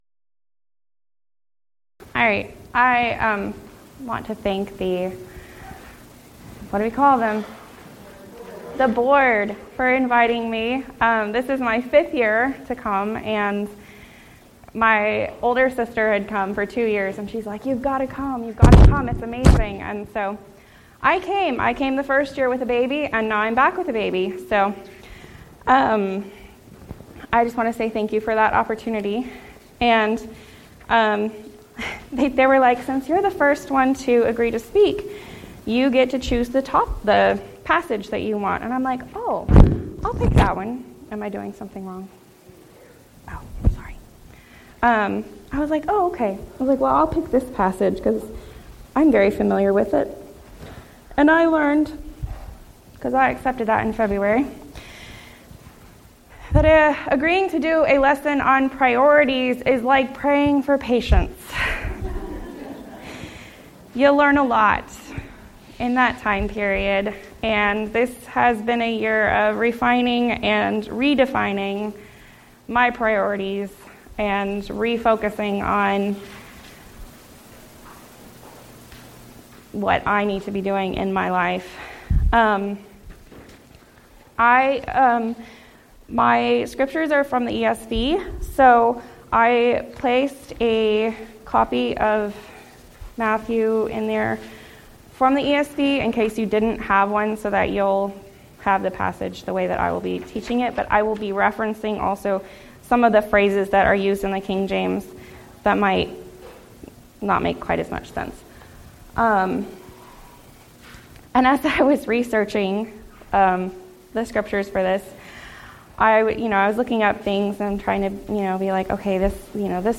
Event: 10th Annual Texas Ladies in Christ Retreat
Ladies Sessions